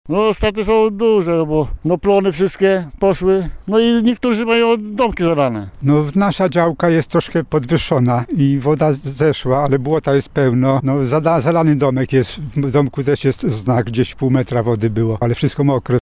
By zdobyć materiał, nasz reporter zdjął buty, podciągnął spodnie i poszedł porozmawiać z działkowiczami:
działkowicze